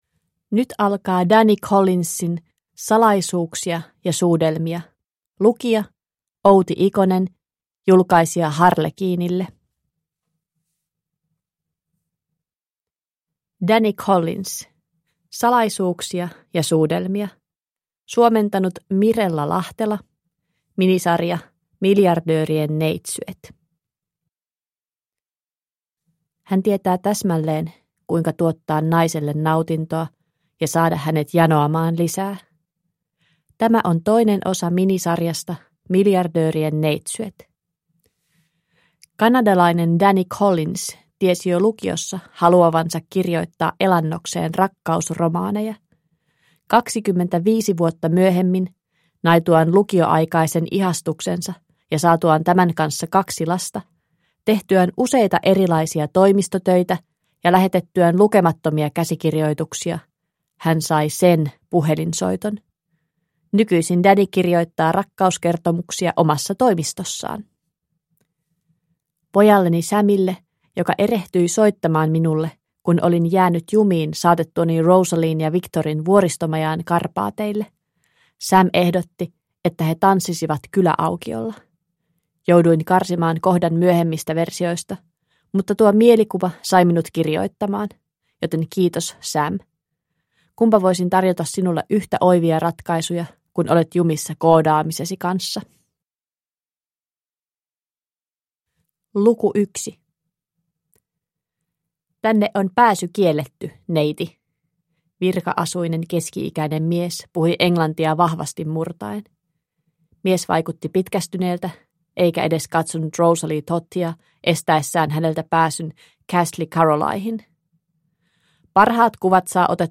Salaisuuksia ja suudelmia – Ljudbok – Laddas ner